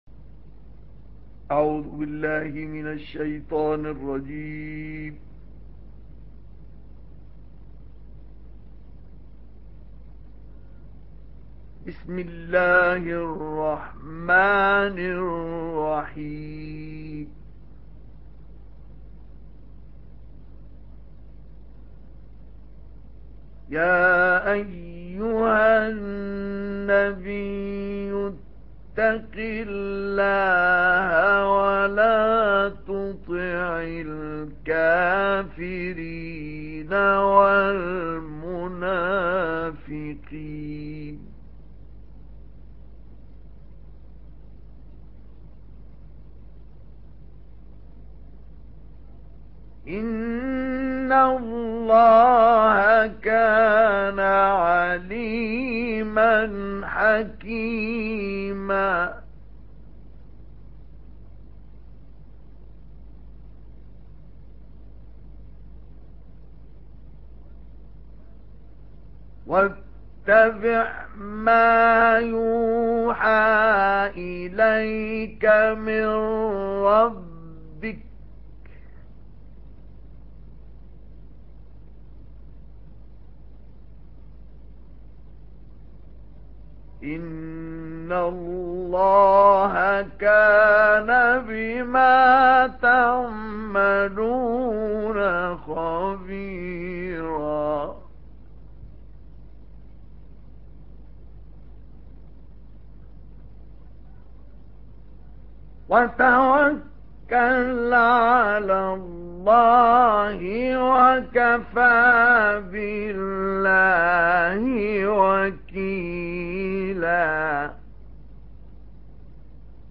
دانلود تلاوت سوره احزاب آیات 1 الی 24 با صدای ماندگار استاد مصطفی اسماعیل
در این بخش از ضیاءالصالحین، تلاوت زیبای آیات 1 الی 24 سوره مبارکه احزاب را با صدای دلنشین استاد شیخ مصطفی اسماعیل به مدت 27 دقیقه با علاقه مندان به اشتراک می گذاریم.